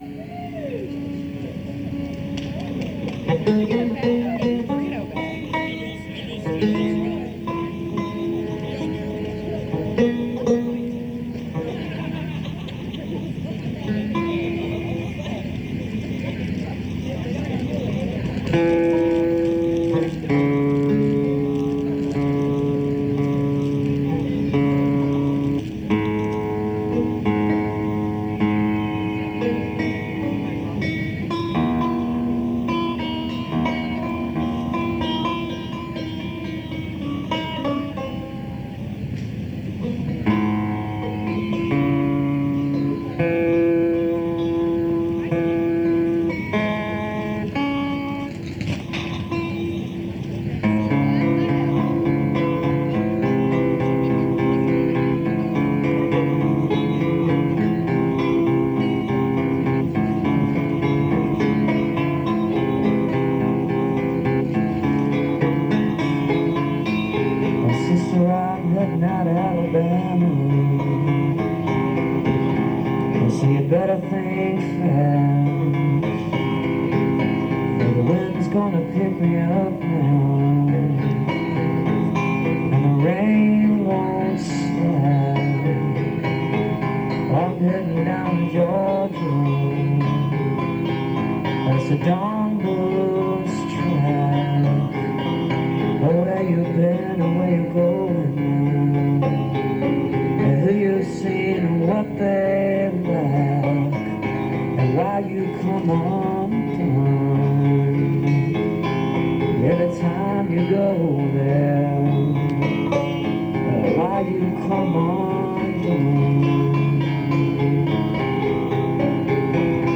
(soundcheck)